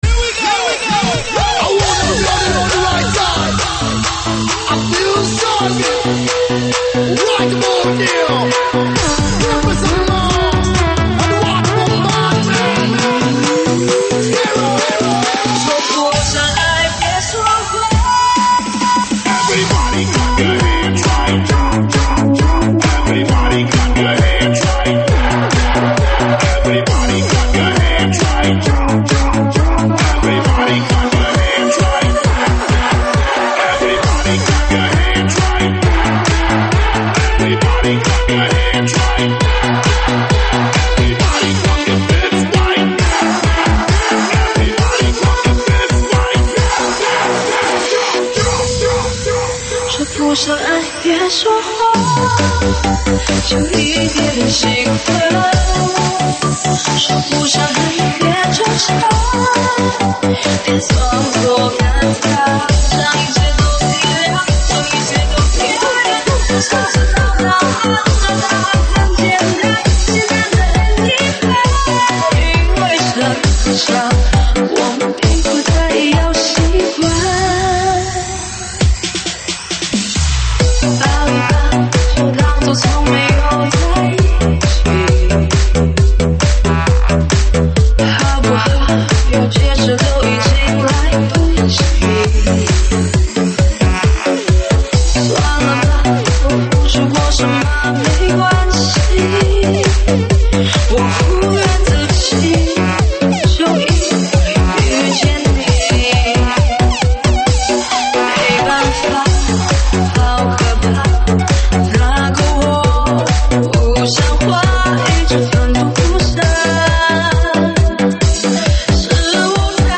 上传于：2025-09-03 07:04，收录于(现场串烧)提供在线试听及mp3下载。